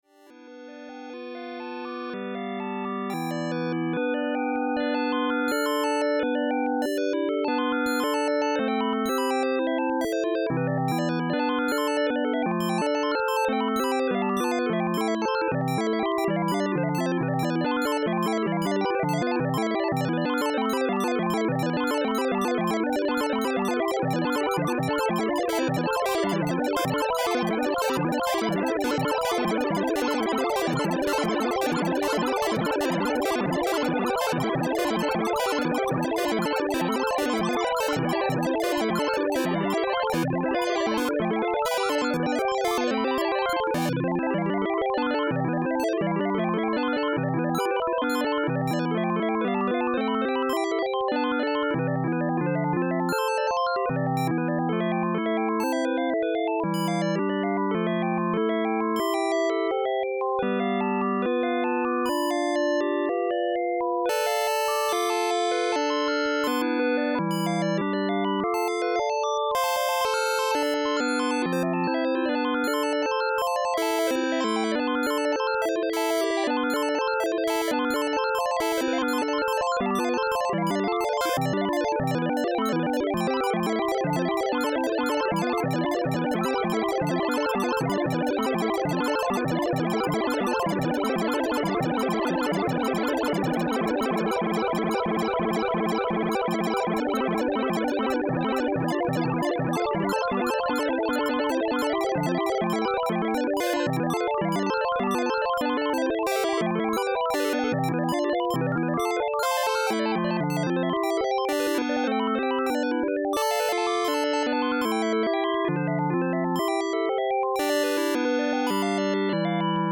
The day I finally figured out how to have smoothly varying tempo in my ChucK improvisations:
Filed under: Instrumental | Comments (2)